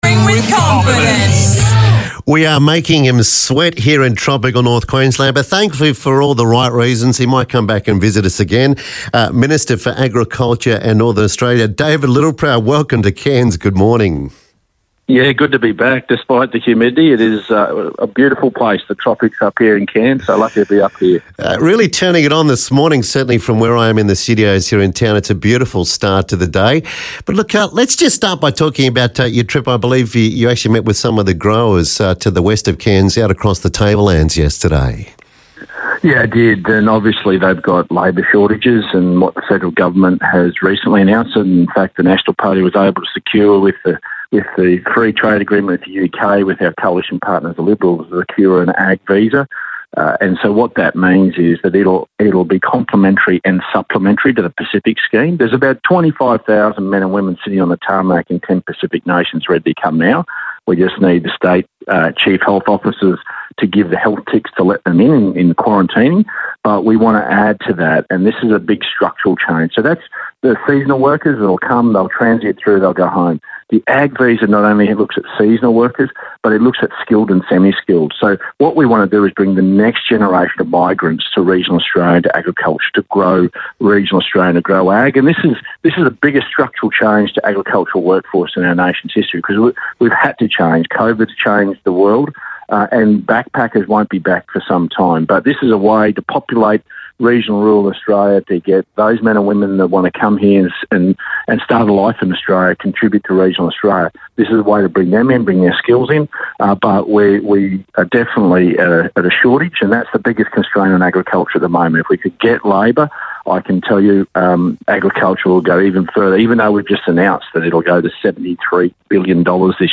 speaks with Minister for Agriculture and Northern Australia David Littleproud about his trip to Cairns and nett zero emissions by 2050